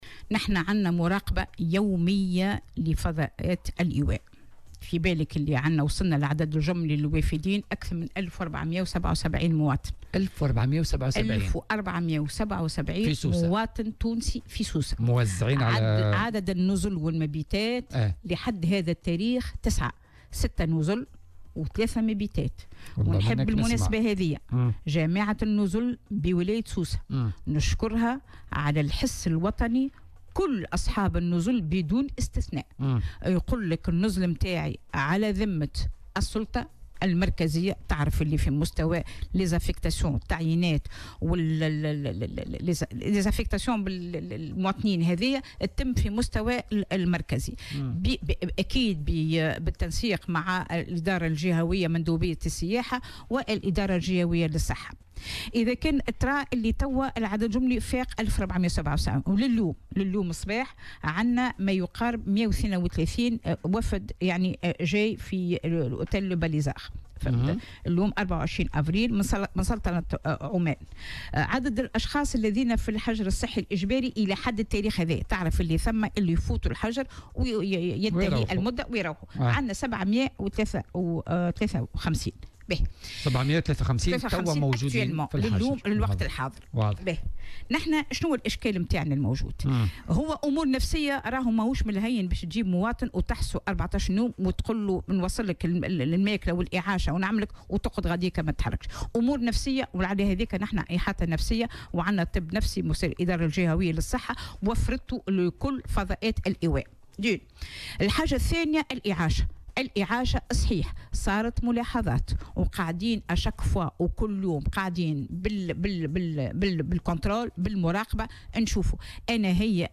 وأضافت في مداخلة لها اليوم في برنامج "بوليتيكا" أنه من المنتظر أيضا وصول 132 تونسيا اليوم إلى الجهة من سلطة عمان، مثمنة في هذا السياق المجهود الذي تقوم به جامعة النزل و أصحاب النزل في معاضدة مجهودات الدولة.